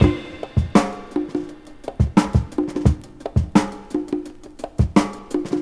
• 85 Bpm Fresh Drum Beat E Key.wav
Free breakbeat sample - kick tuned to the E note. Loudest frequency: 611Hz
85-bpm-fresh-drum-beat-e-key-7Tr.wav